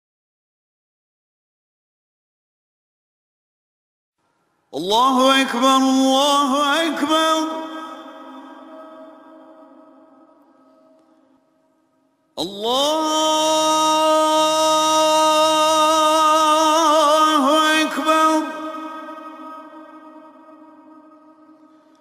На этой странице собраны записи Азана — проникновенного исламского призыва к молитве.
Слушаем Азан: начало мусульманской молитвы